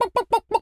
chicken_cluck_bwak_seq_13.wav